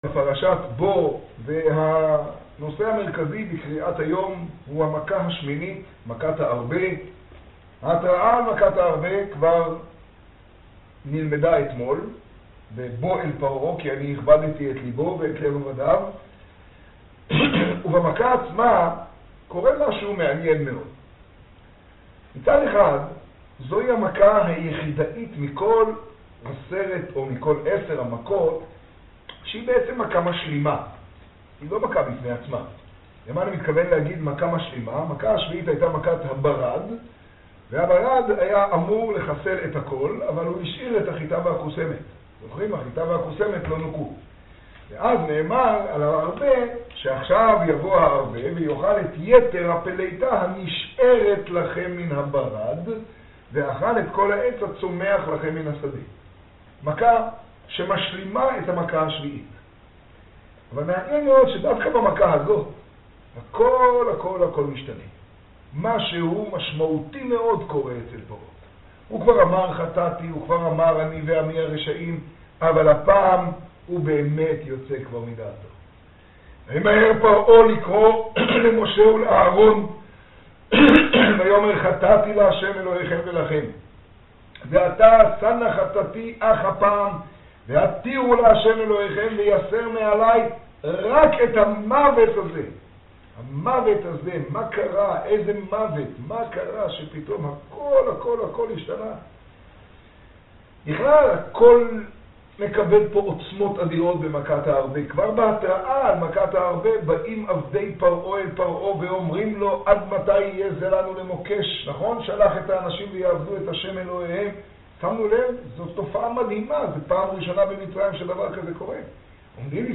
לימוד פרשת בא תשעה.